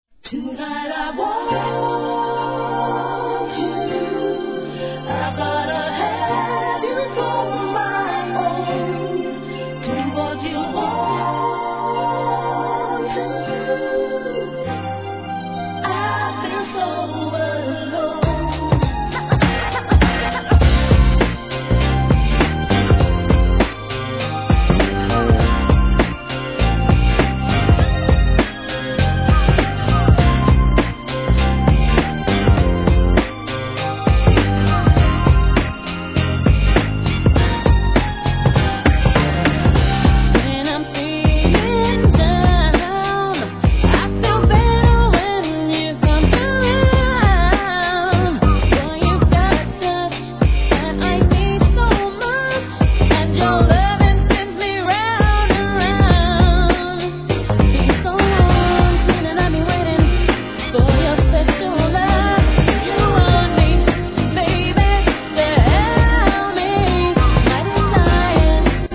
HIP HOP/R&B
ダンサブルなBEATにドラマチックなメロディーが胸キュン間違い無しの怒キャッチーR&B!